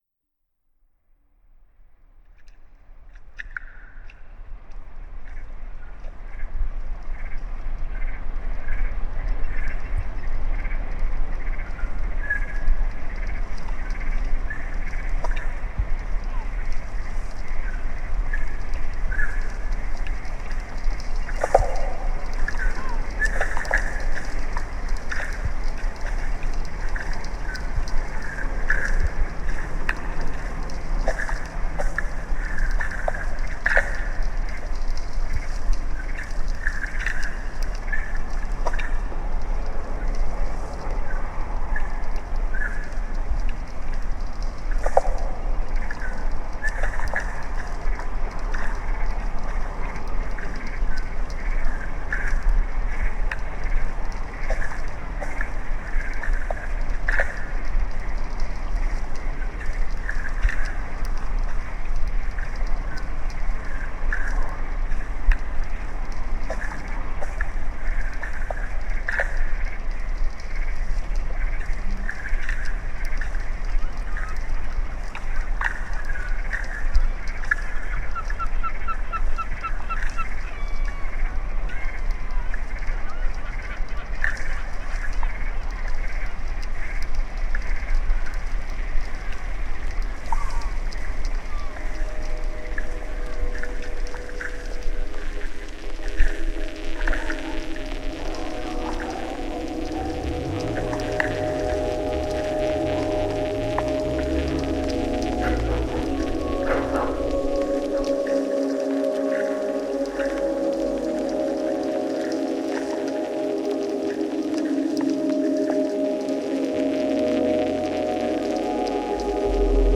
Genre: Ambient.